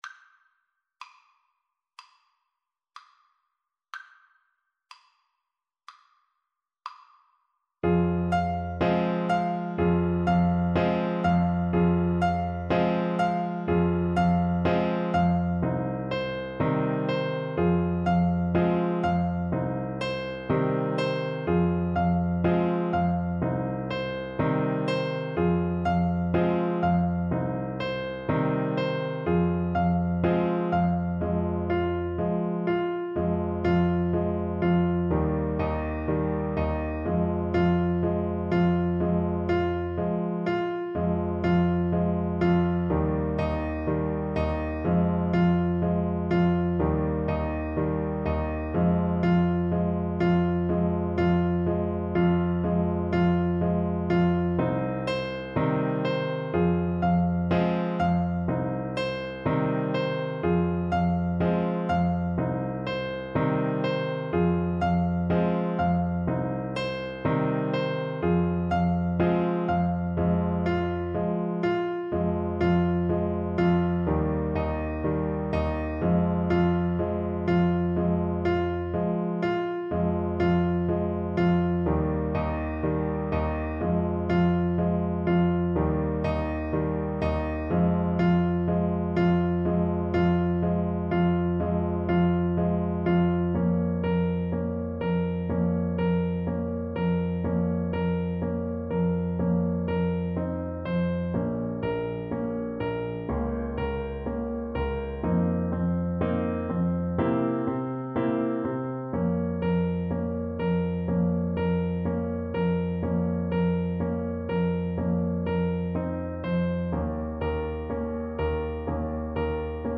4/4 (View more 4/4 Music)
Arrangement for Clarinet and Piano
Pop (View more Pop Clarinet Music)